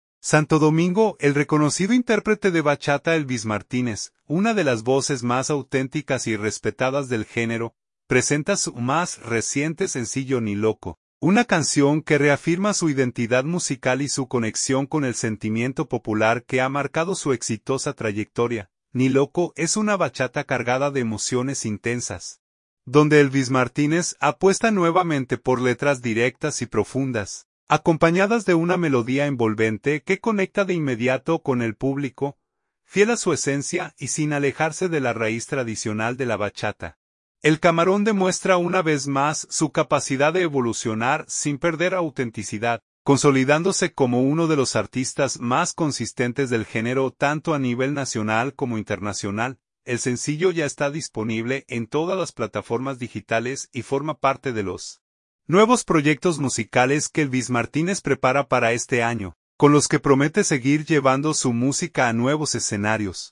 bachata
es una bachata cargada de emociones intensas